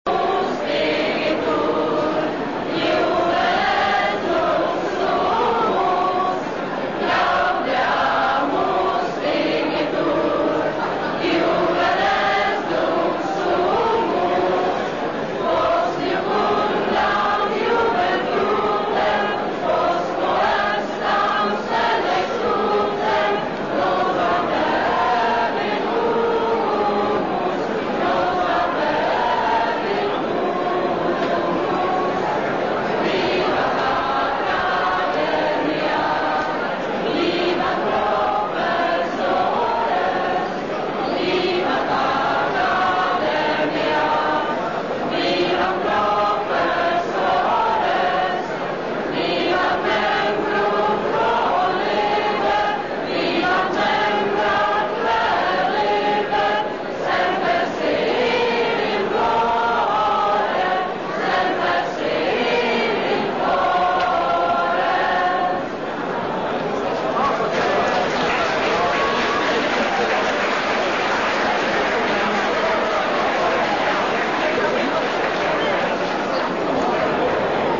trubka a zpěv
housle a zpěv
flétna a zpěv
Gaudeamus Igitur (s 22 hosty)
kvileni.mp3